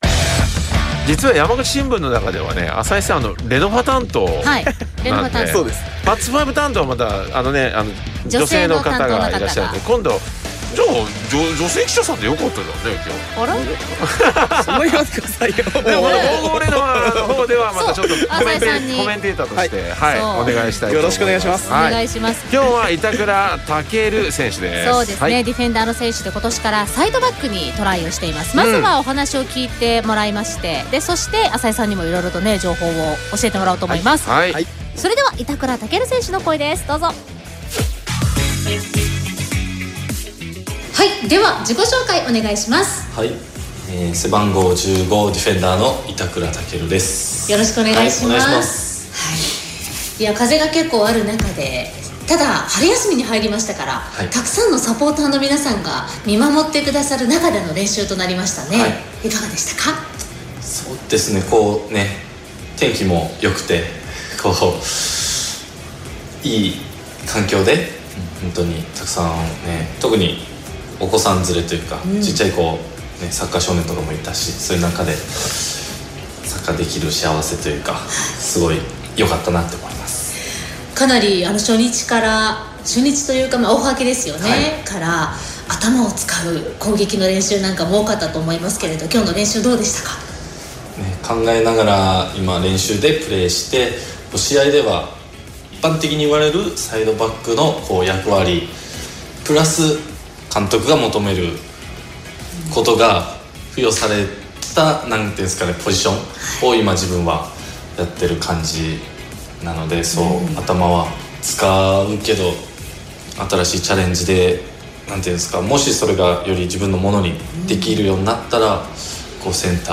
リポーター